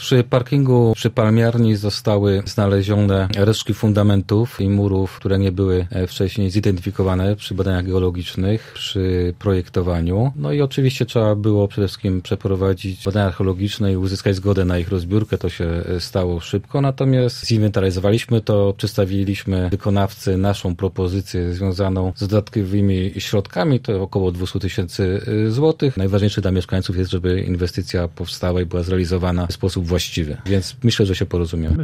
– Rozmawiamy z wykonawcą o wznowieniu prac – tłumaczy Krzysztof Kaliszuk, wiceprezydent Zielonej Góry: